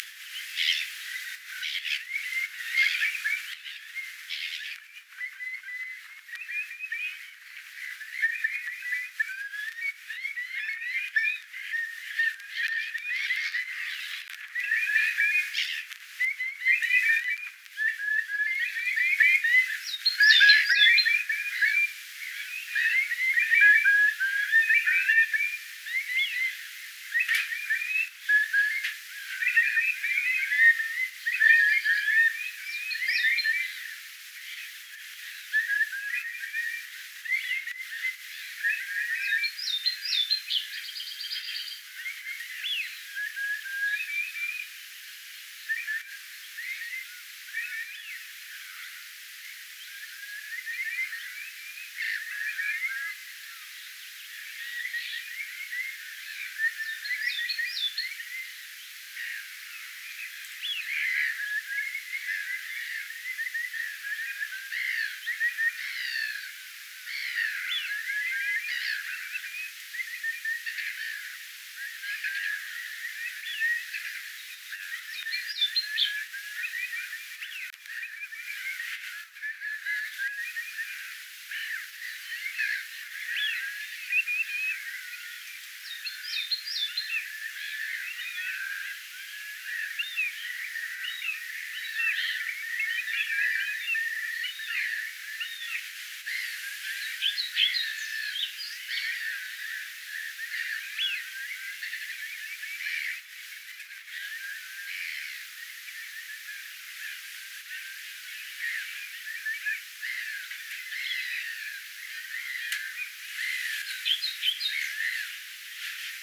kuovit nousevat muutolle äännellen
hyvin korkealta taivaalta melkein pilvien korkeudelta,
kuovit_nousevat_aannellen_muutolle.mp3